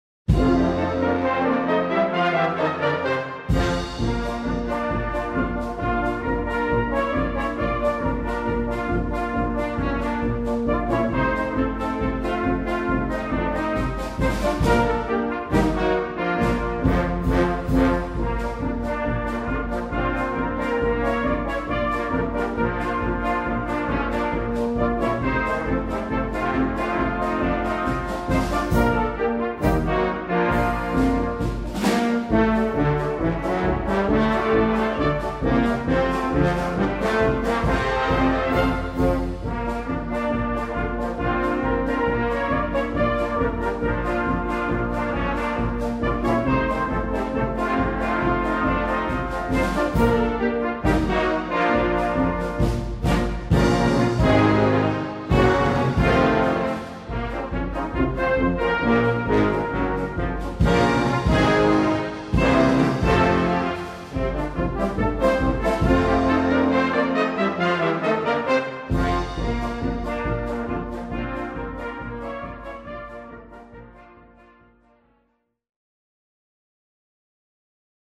A band in my office started playing